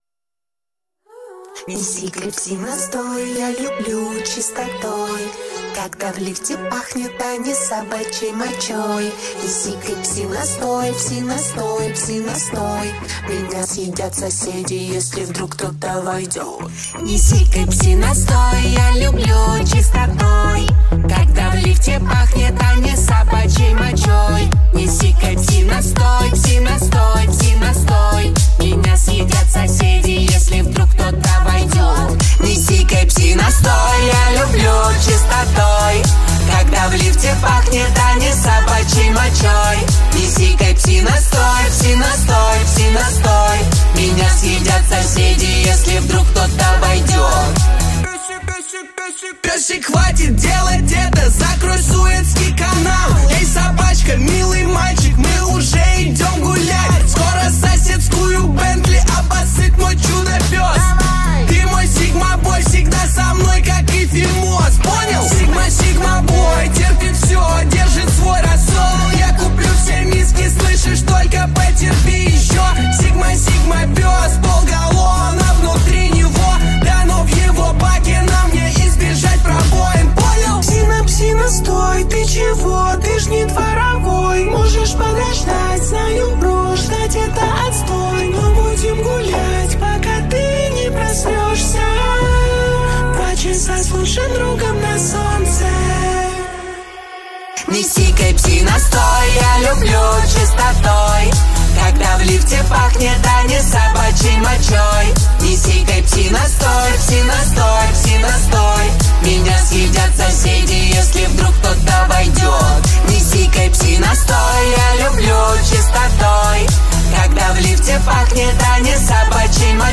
Пародия.